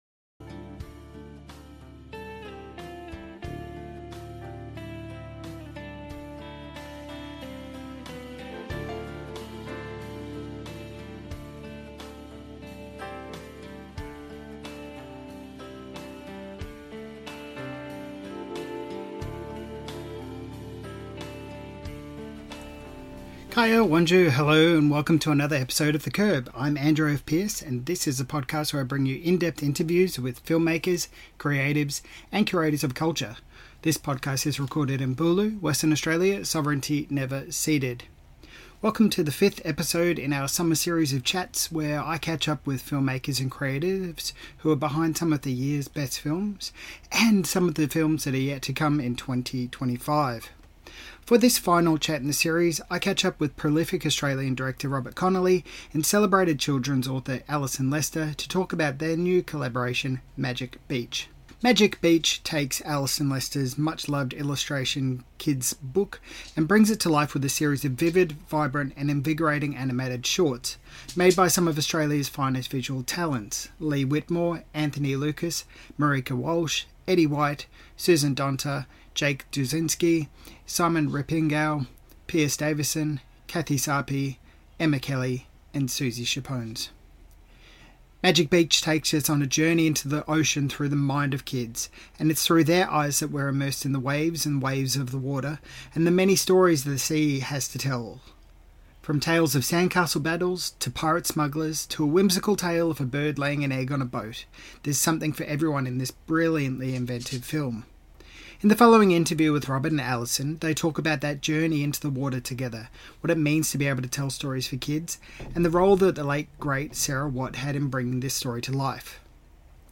Robert Connolly & Alison Lester Talk About Journeying Down to Magic Beach in This Interview - The Curb | Film and Culture